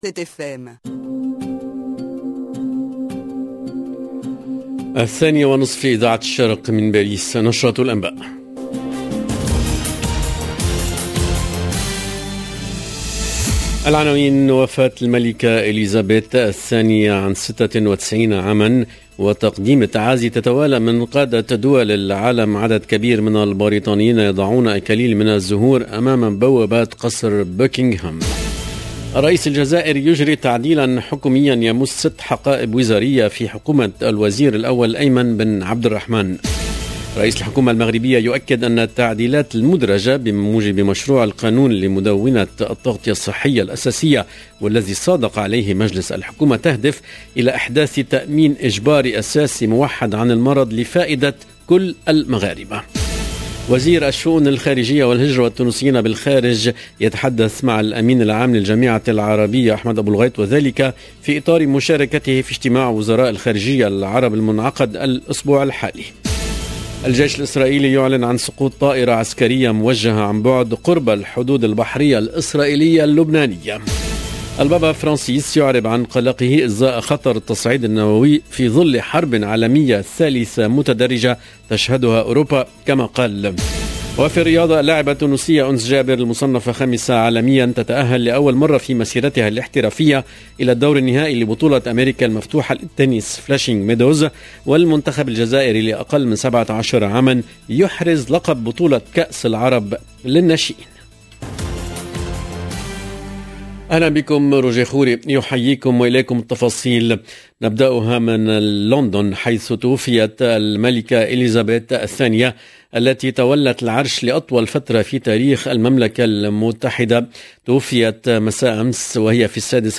LE JOURNAL EN LANGUE ARABE DE LA MI-JOURNEE DU 9/09/22